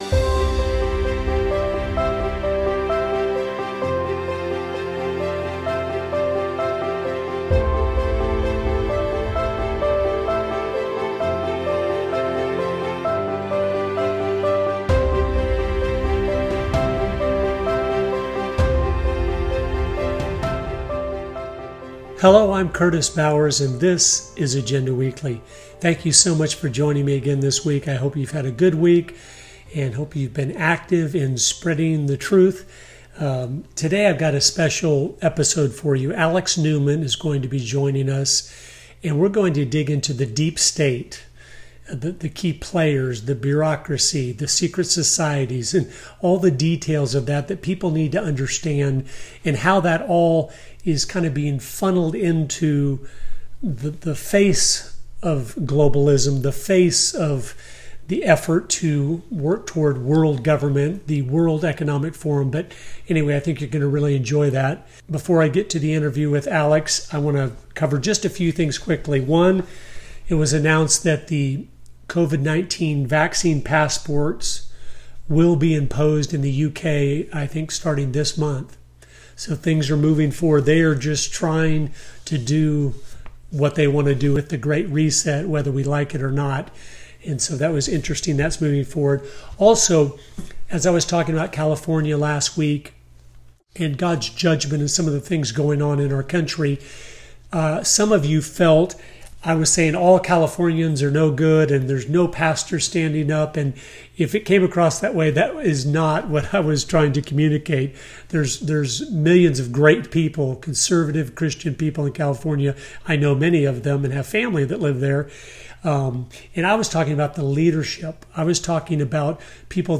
Agenda Weekly: Interview